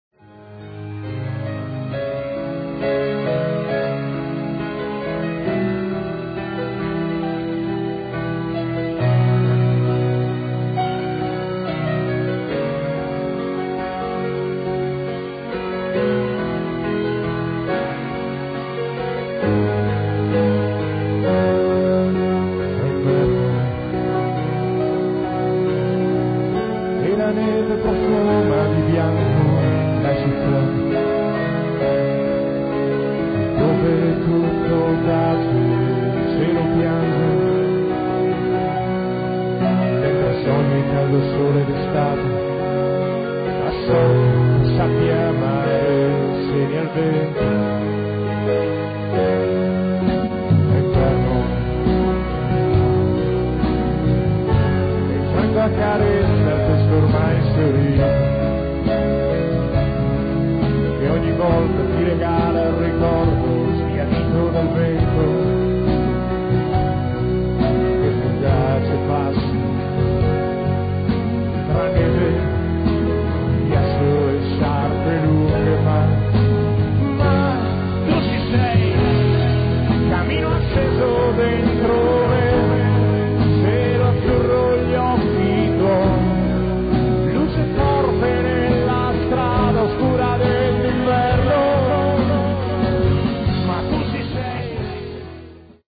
alla chitarra e alla voce
alla batteria
al basso
alle tastiere